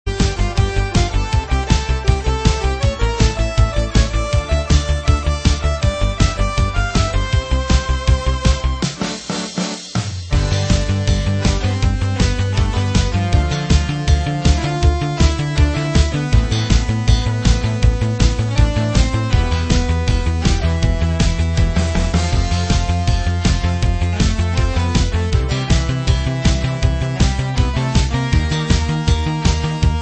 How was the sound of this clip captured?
[Lo-Fi preview] Remixers Website